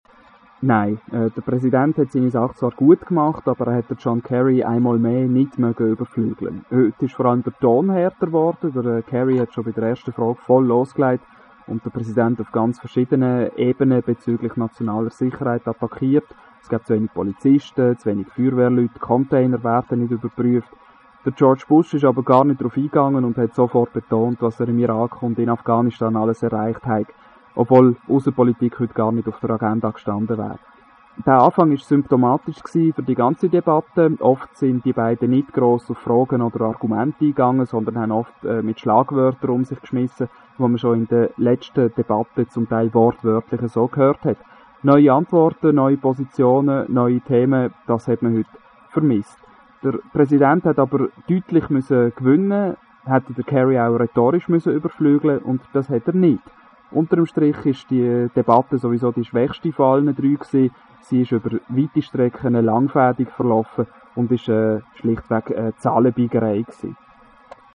Und dann also die Final Presidential Debate - verfolgt in Bennington, Vermont. meine Eindrücke gleich im O-Ton, so wie ich sie Radio ExtraBern geschildert habe:
Diese Quotes wurden natürlich auseinandergeschnipselt, das wäre alles viiiel zu viel.